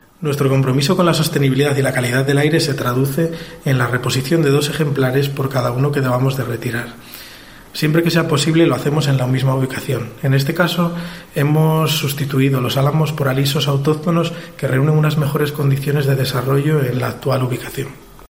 Pelayo García, concejal de sostenibilidad